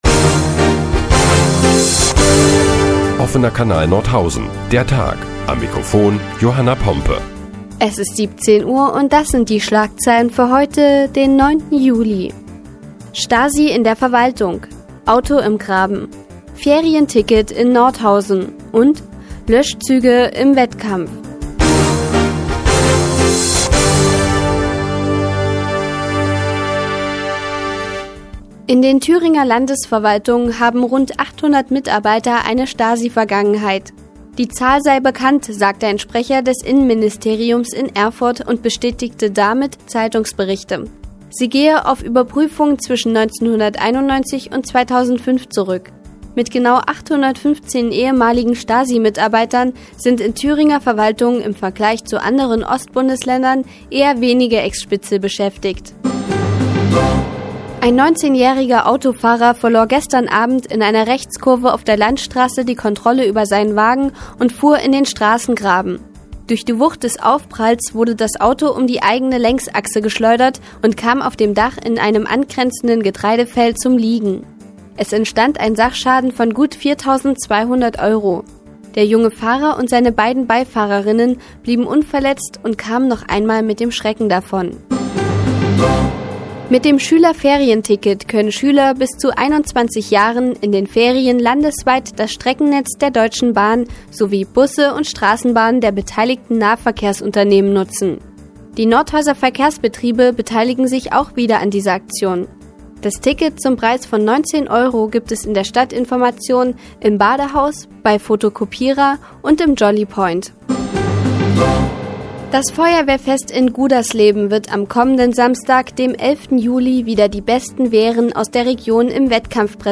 Die tägliche Nachrichtensendung des OKN ist nun auch in der nnz zu hören. Heute geht es unter anderem um ehemalige Stasi-Mitarbeiter in der Thüringer Verwaltung und ein günstiges Ferienticket für Schüler.